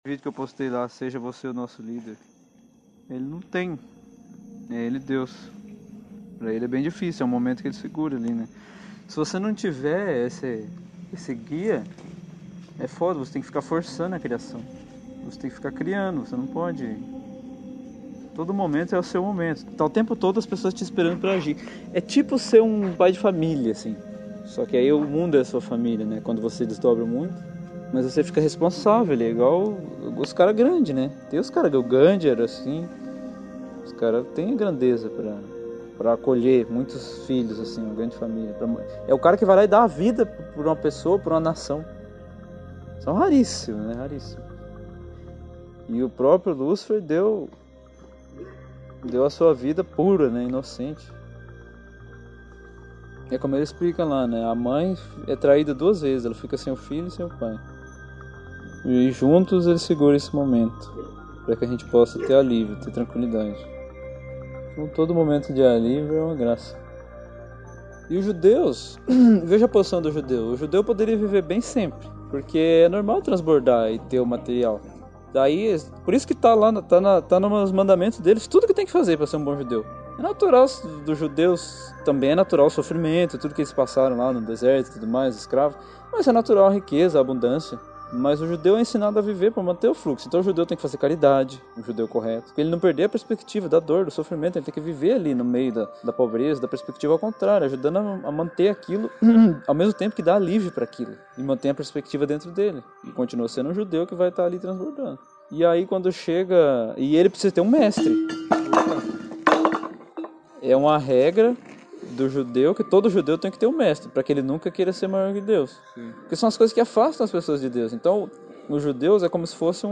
Dê play no podcast abaixo e ouça nossa conversa sobre lógica, lealdade, nossas personalidades, o modo como vemos e fazemos a obra viva de Deus, e a oração recitada ao final.